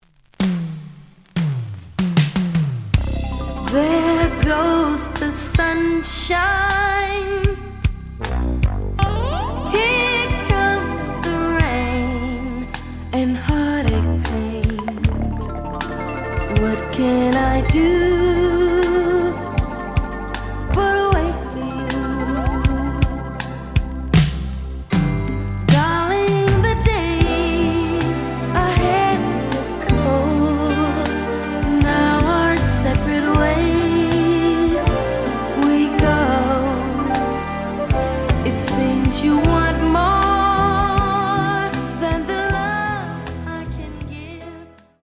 試聴は実際のレコードから録音しています。
The audio sample is recorded from the actual item.
●Genre: Soul, 80's / 90's Soul